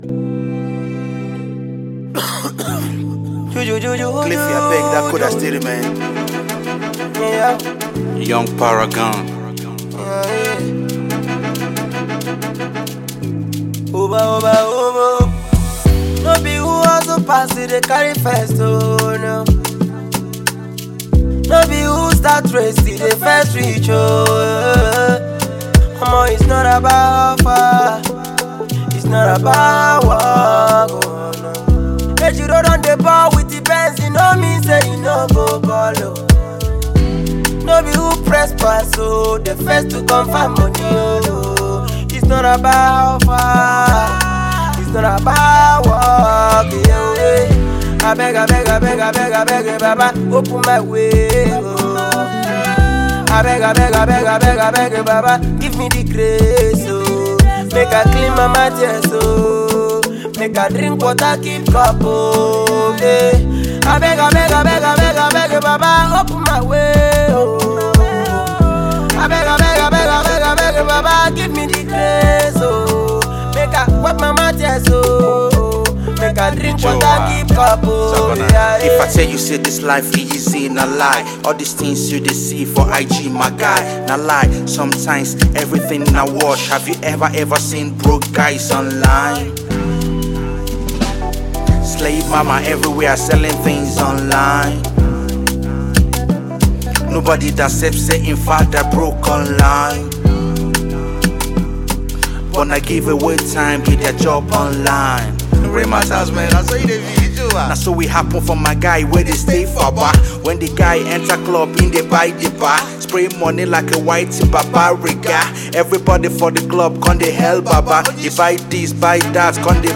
Rapper
motivational jam